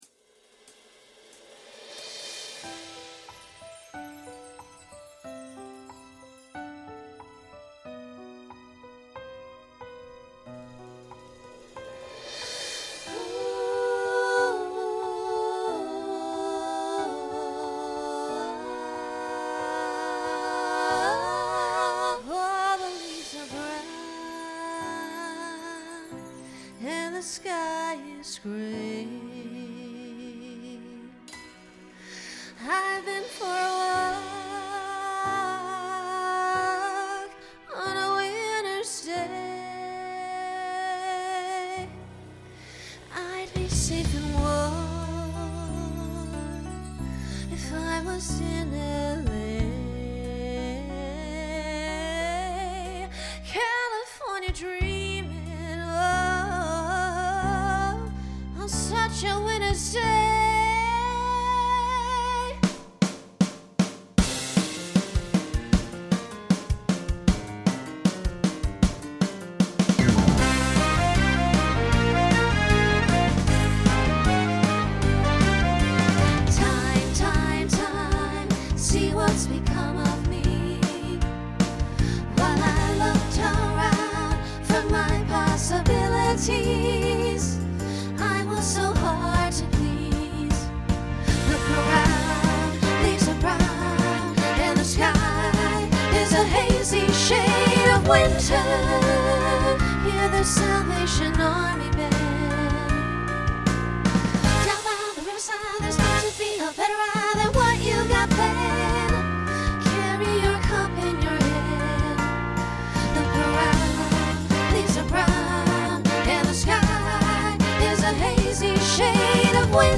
Genre Rock Instrumental combo
Voicing SSA